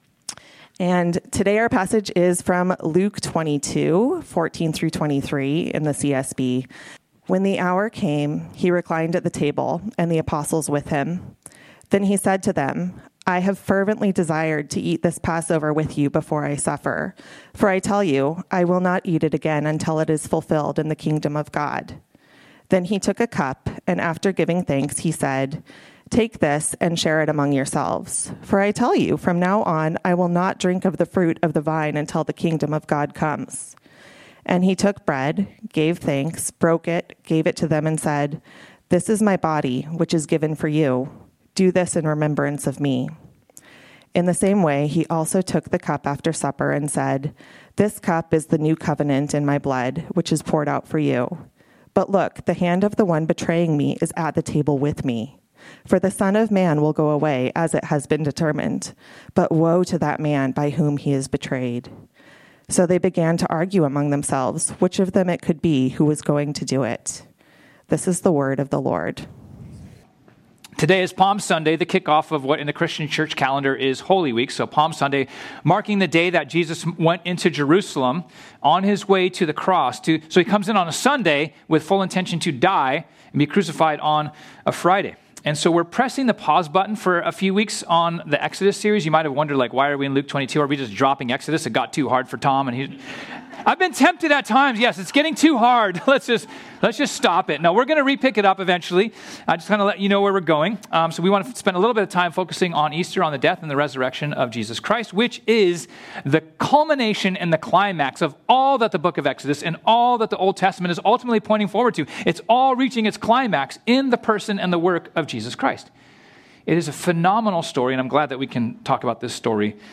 This sermon was originally preached on Sunday, April 13, 2025.